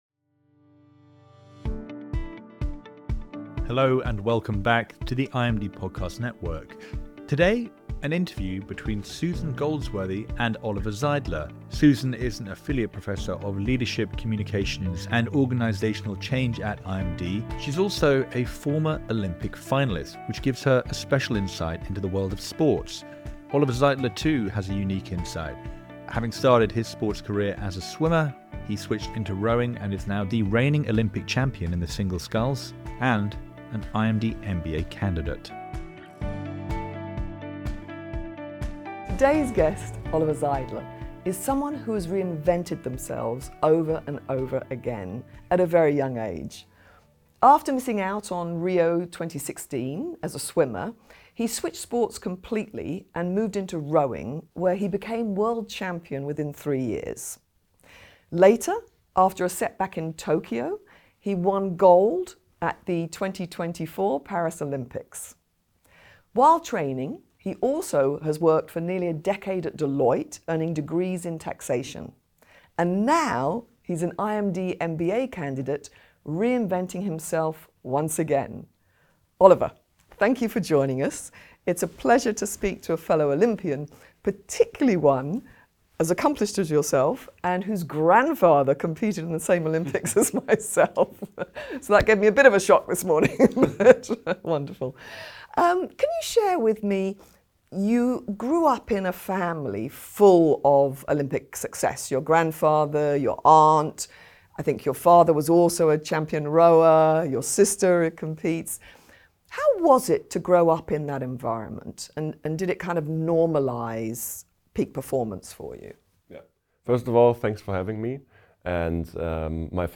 Oliver-Zeidler-The-Interview-Podcast-2.mp3